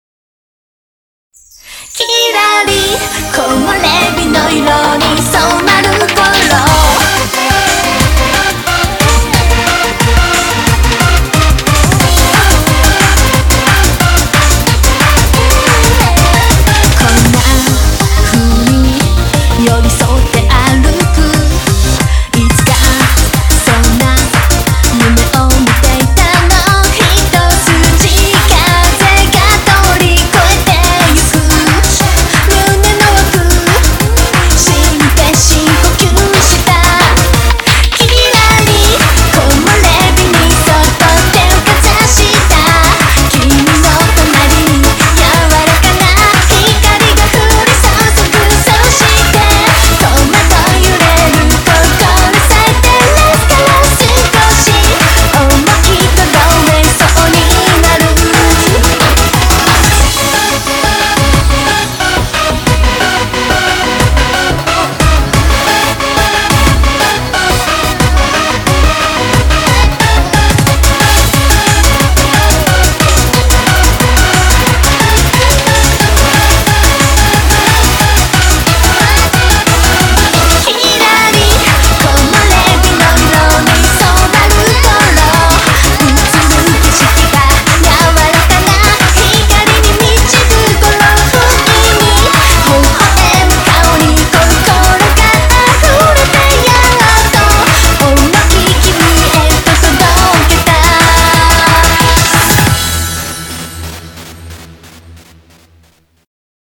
BPM90-180